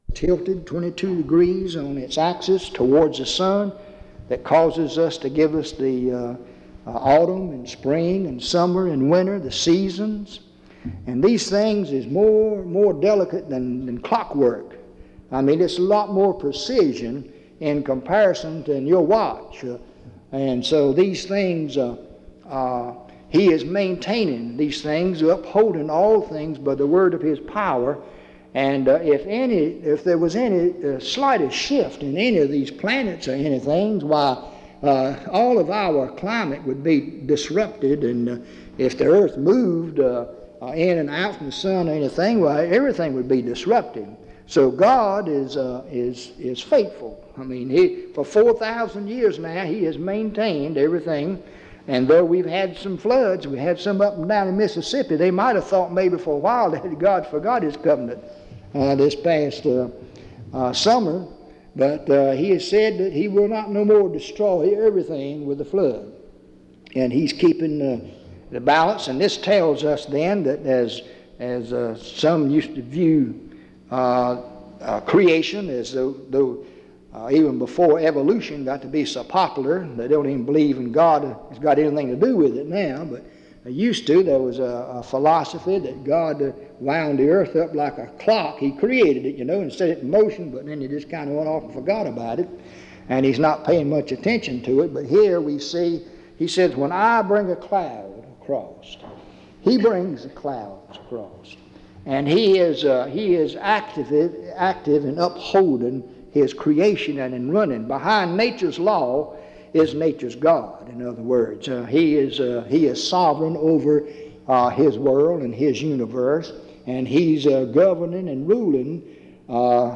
In Collection: Reidsville/Lindsey Street Primitive Baptist Church audio recordings Miniaturansicht Titel Hochladedatum Sichtbarkeit Aktionen PBHLA-ACC.001_029-A-01.wav 2026-02-12 Herunterladen PBHLA-ACC.001_029-B-01.wav 2026-02-12 Herunterladen